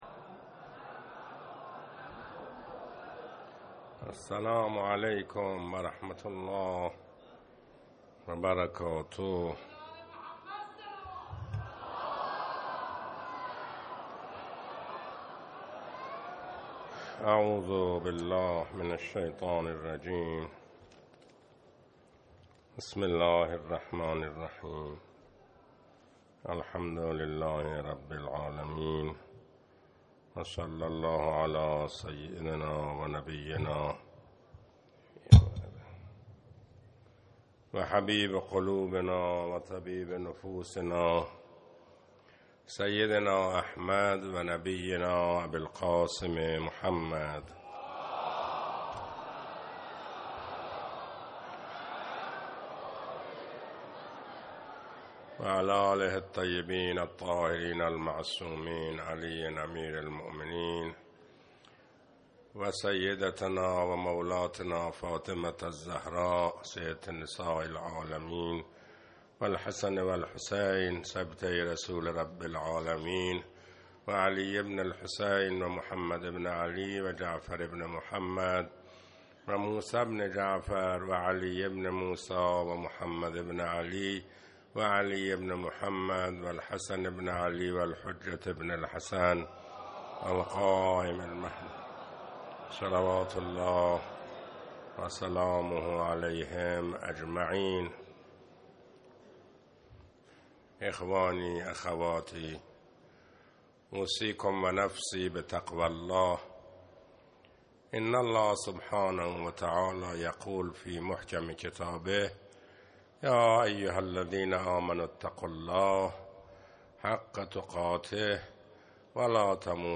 خطبه اول